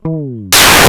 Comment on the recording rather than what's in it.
They were recorded with my custom Kaminski 4-string fretless.